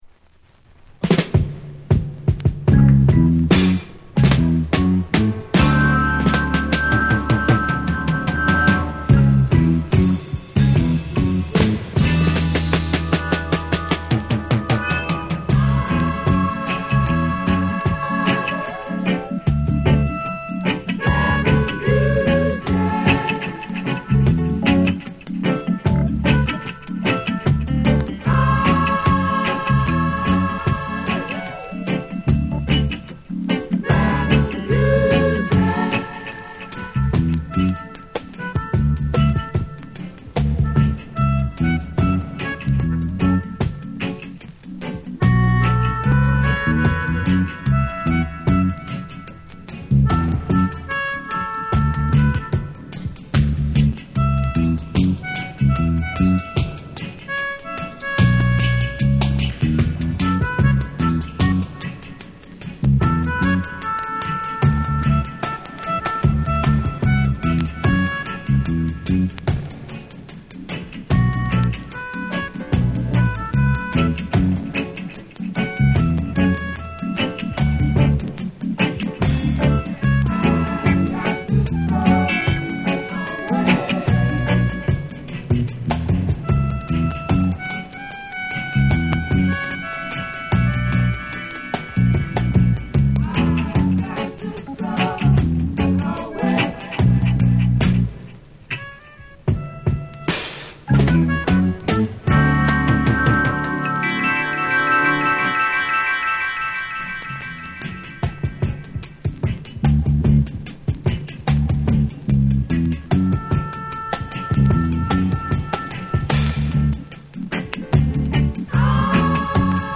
彼ならではの浮遊感あるメロディカが、幻想的に響き渡るスピリチュアルなダブの世界。